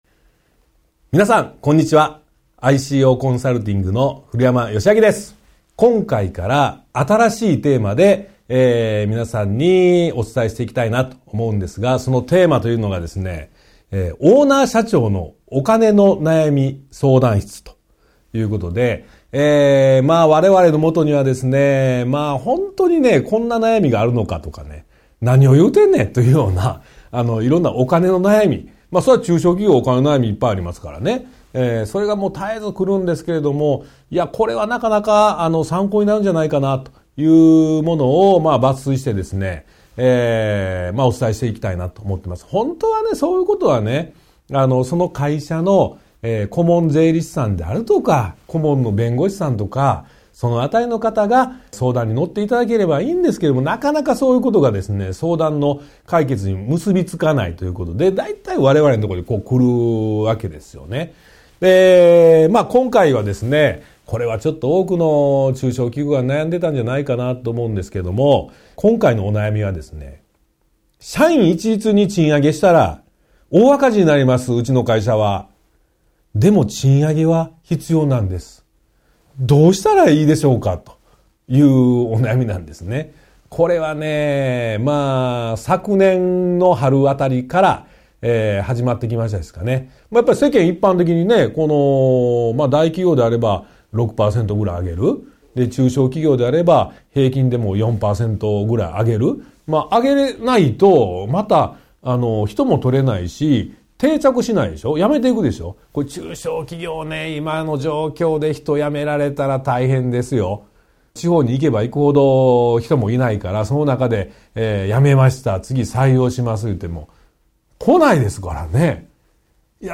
ワンポイント音声講座 相談1：社員一律に賃上げしたら、大赤字になります！